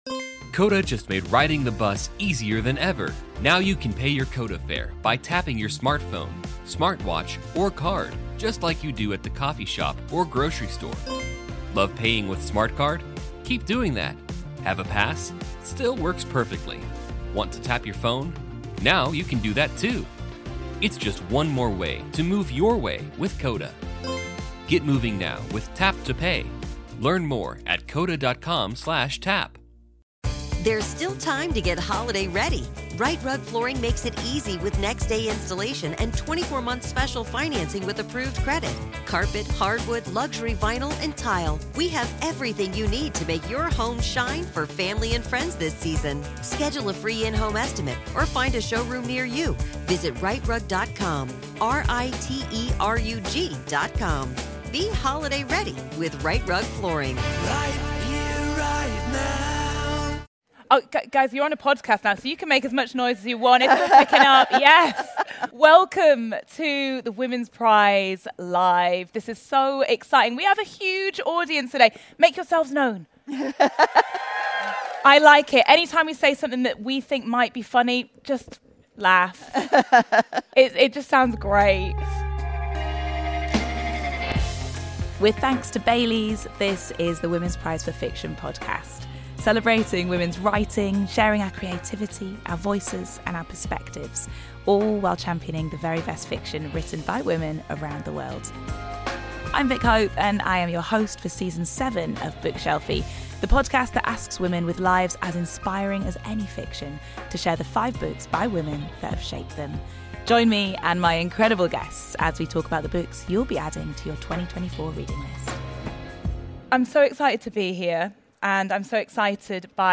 The former Prime Minister of Australia, Julia Gillard joins Vick for a live recording in Bedford Square Gardens from the Women’s Prize Live Festival.